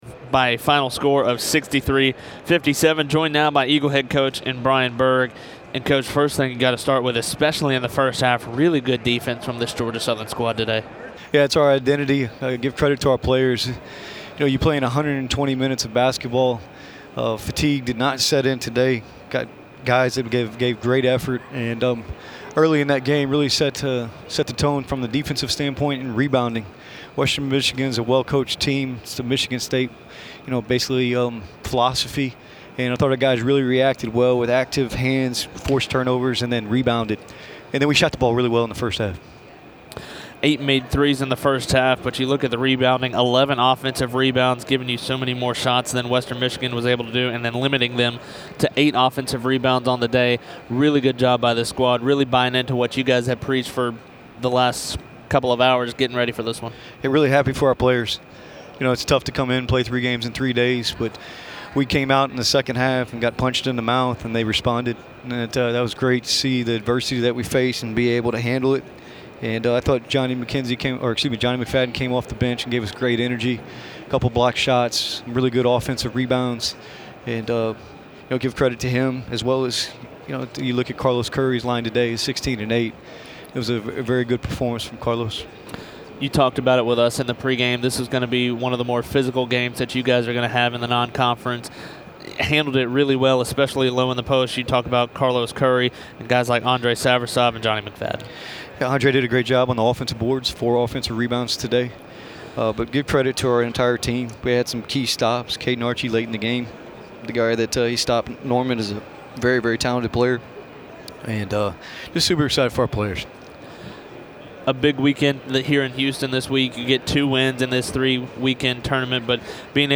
Postgame radio interview